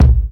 DrKick88.wav